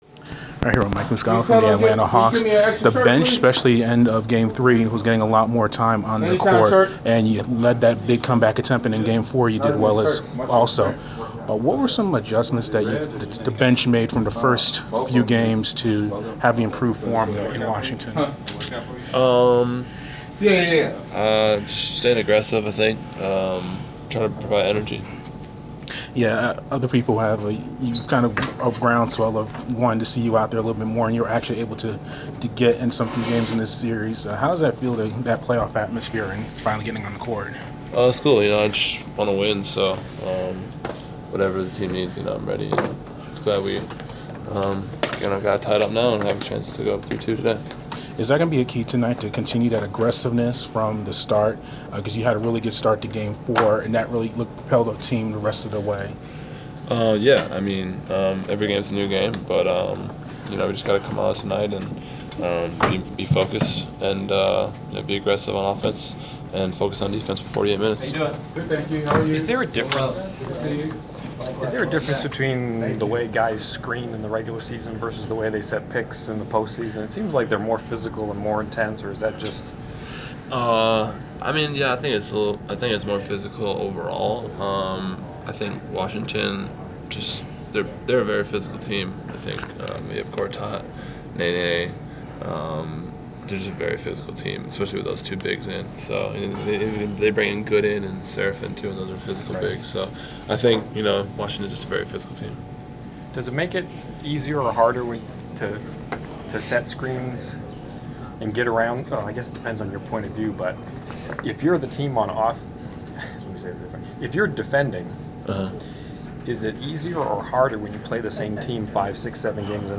Inside the Inquirer: Pregame interview with Atlanta Hawks’ Mike Muscala 5.13.15
The Sports Inquirer spoke with Atlanta Hawks’ forward Mike Muscala before his team’s home playoff contest against the Washington Wizards on May 13.